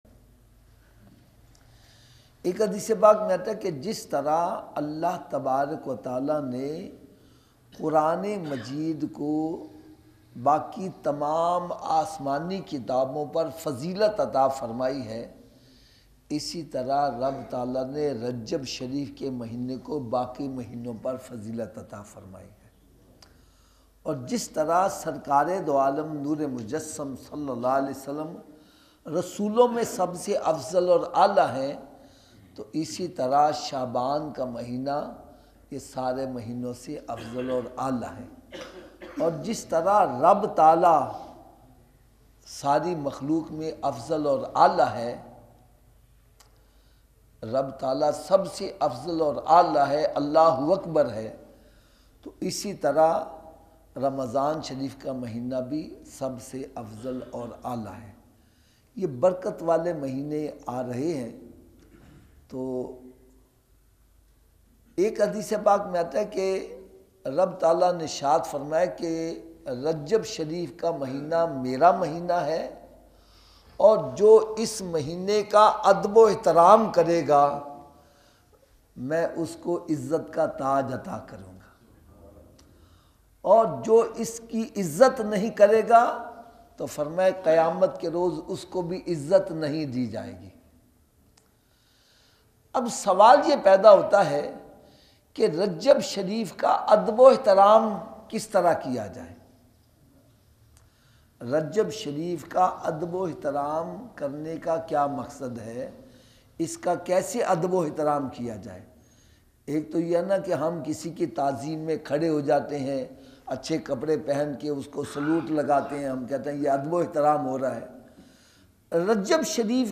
Dars e Quran